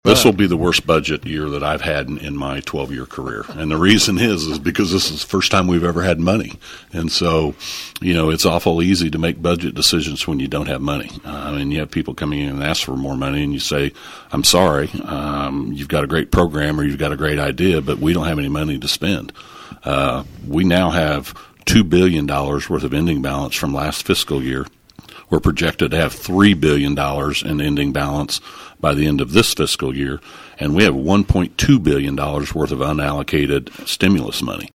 On KVOE’s On-Air Chat recently, 17th District Senator Jeff Longbine of Emporia said redistricting will be a major focal point. Another major talking point will be budget as a whole — with the state in the unusual position of “being flush with cash.”